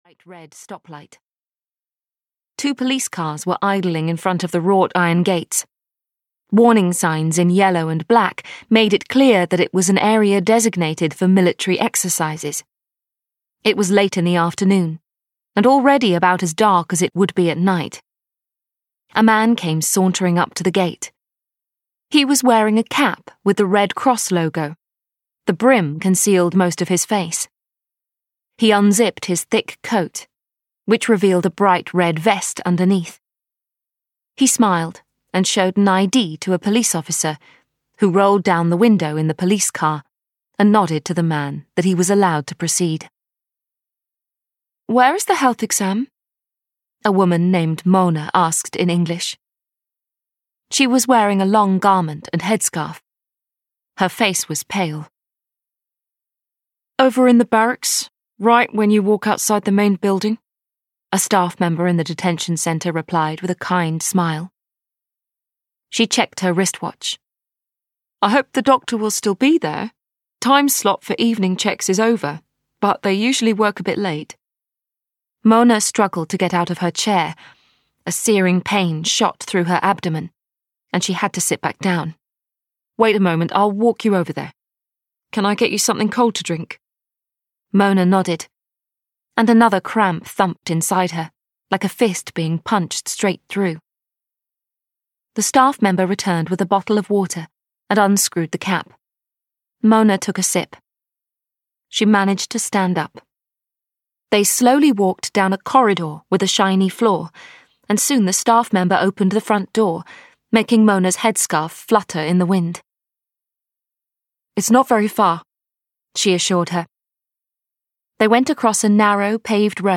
Black Notice: Episode 3 (EN) audiokniha
Ukázka z knihy